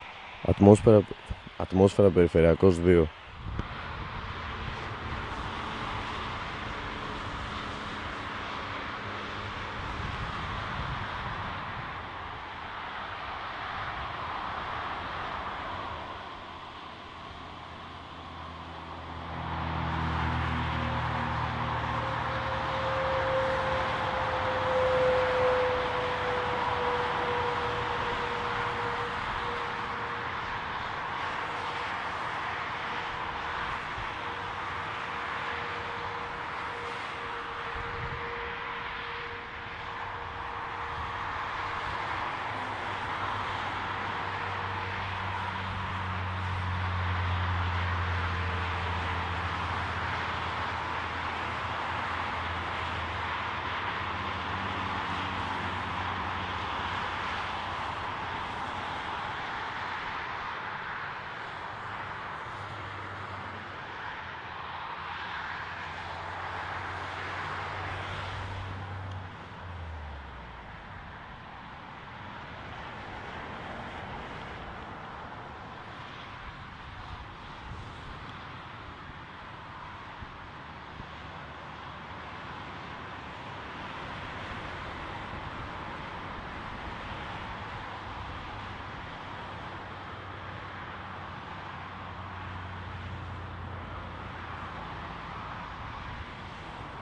环城路交通
描述：公路，交通，汽车，大气
Tag: 公路 交通 大气 城市